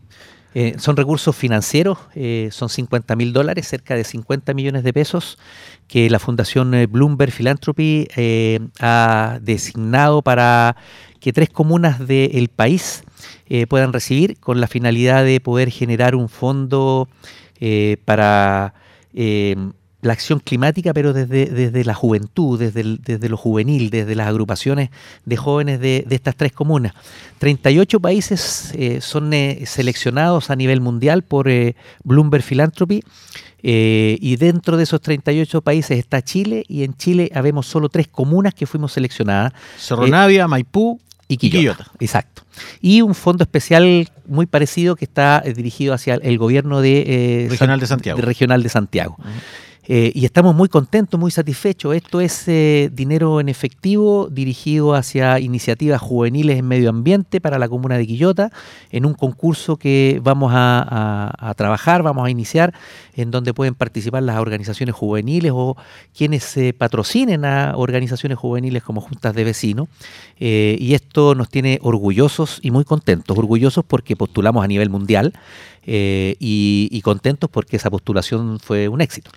QUILLOTA.- En el programa “Abriendo Sentidos” de Radio Quillota, el alcalde Oscar Calderón anunció que Quillota fue una de las tres comunas seleccionadas en Chile por la organización internacional Bloomberg Philanthropies para recibir 50 mil dólares, a través Fondo de Juventud y Acción Climática.